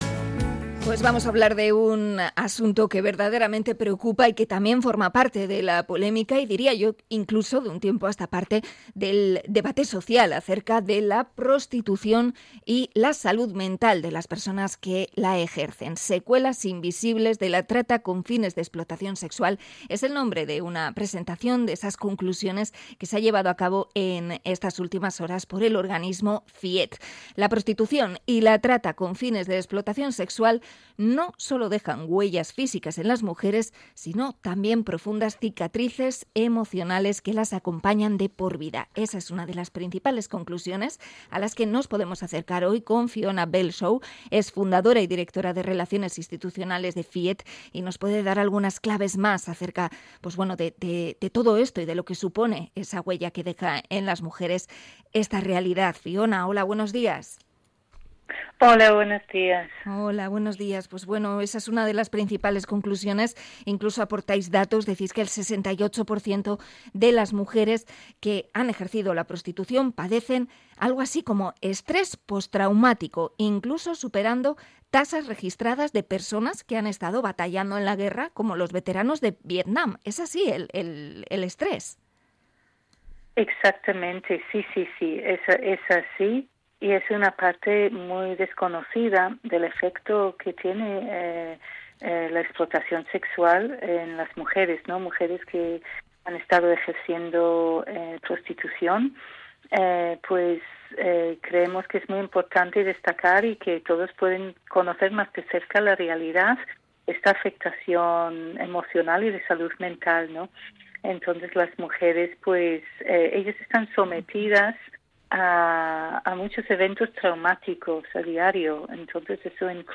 Entrevista a la ONG Fiet por la salud mental de las mujeres prostituidas